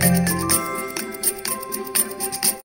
Category: Messages Ringtones